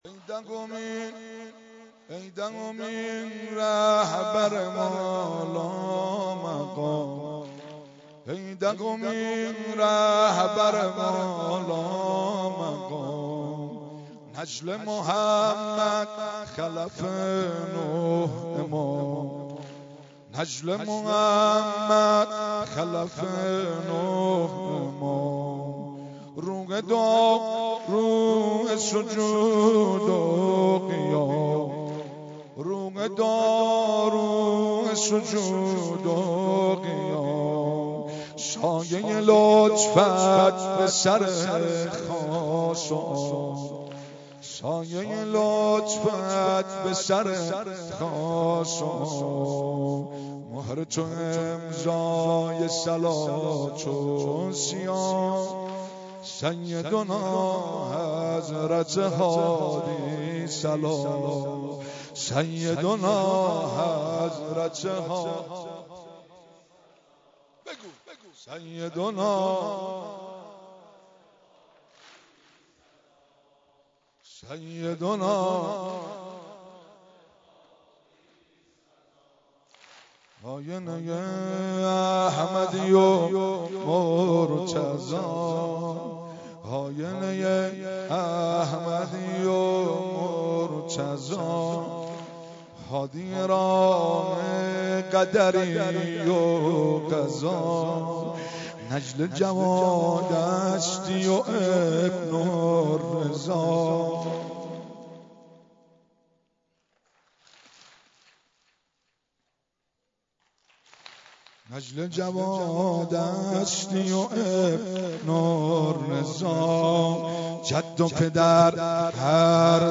سینه زنی خوانی امام هادی